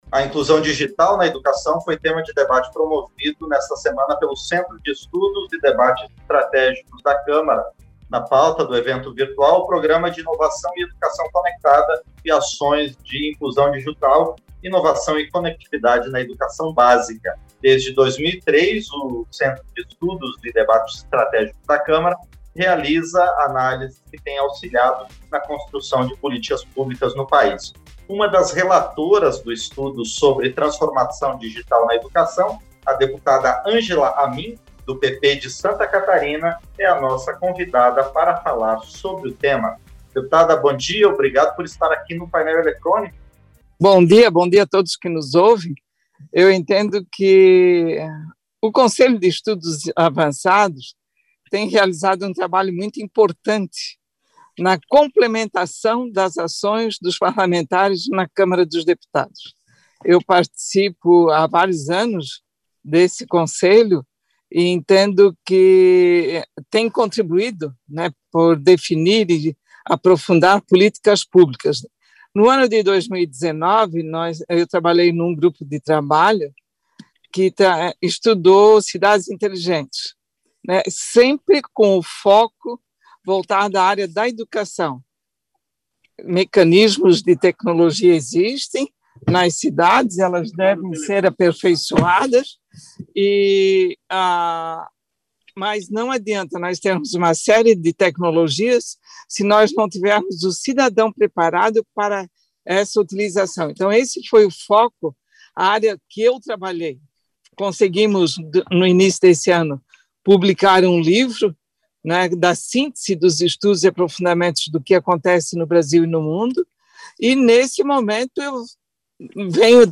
Entrevista - Dep. Angela Amin (PP-SC)